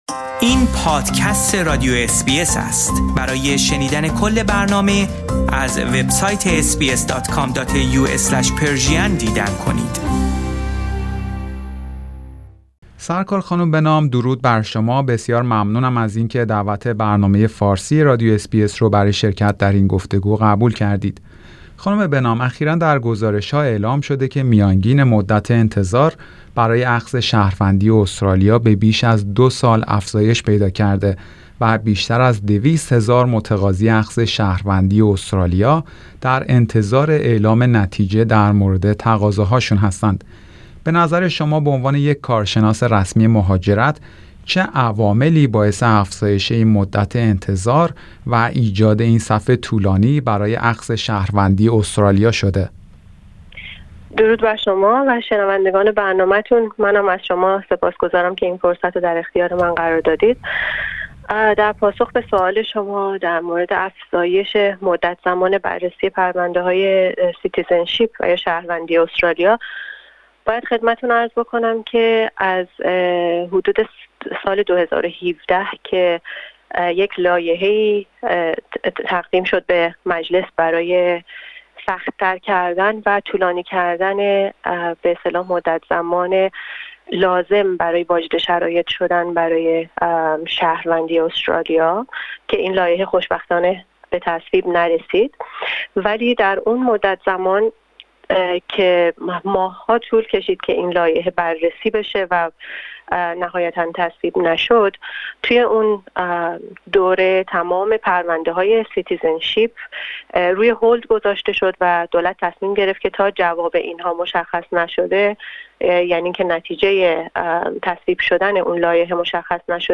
در این گفتگو برخی از عواملی که احتمالا در افزایش مدت انتظار برای اخذ شهروندی نقش داشته اند مورد بررسی قرار گرفته اند.